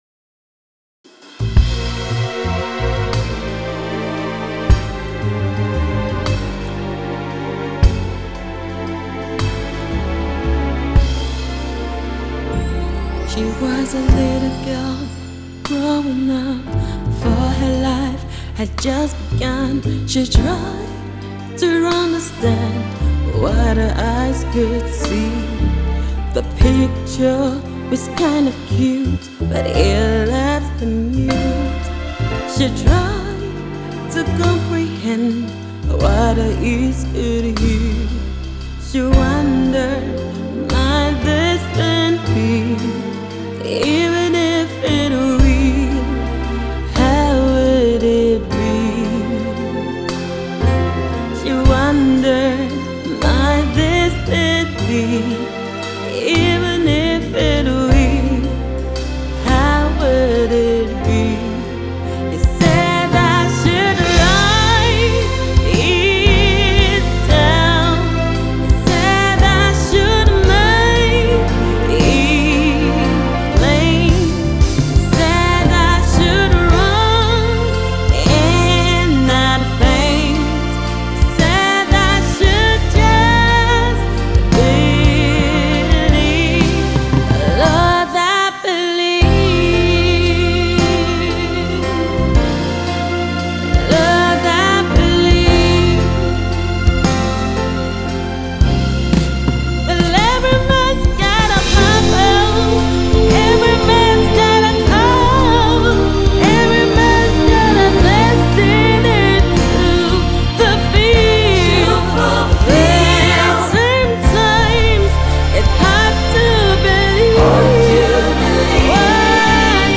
Passionate worshipper and Gospel artiste